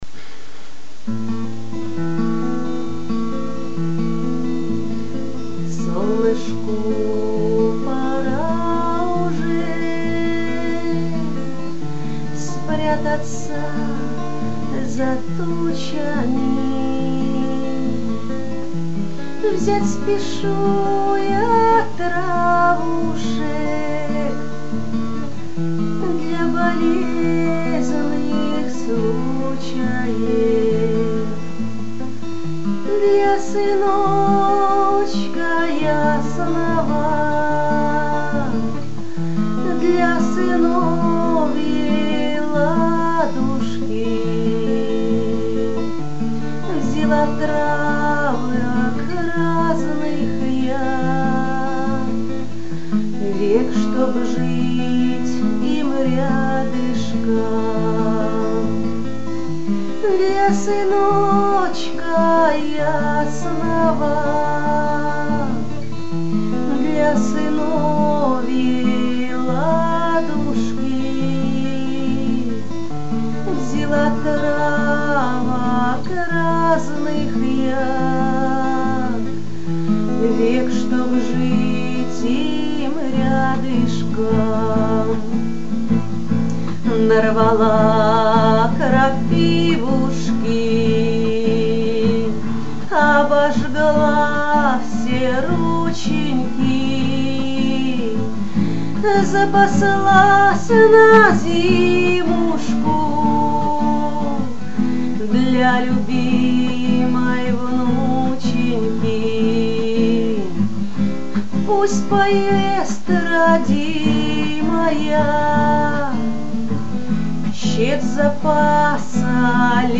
Настоящая народная песня!Что тут говорить, если ваше творчество само говорит за себя! 12 friends friends friends